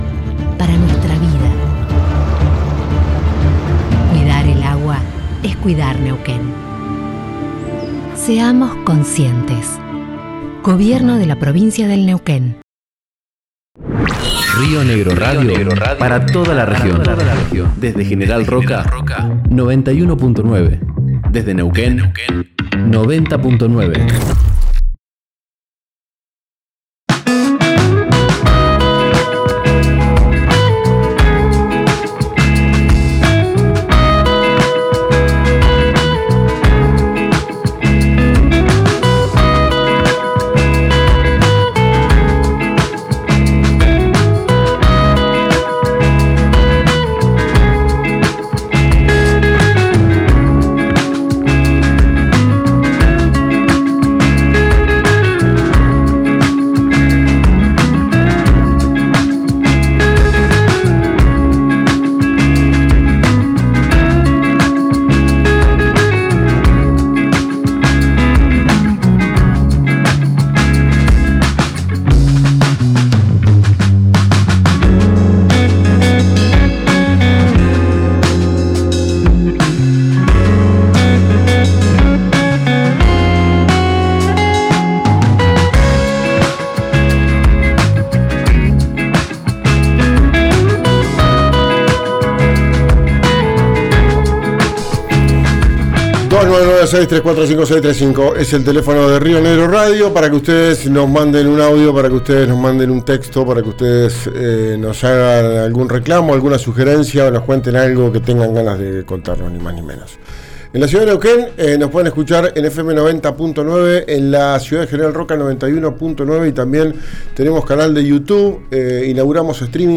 La intendenta Daniela Salzotto, en una entrevista con RADIO RÍO NEGRO, explicó el motivo de los despidos en el municipio.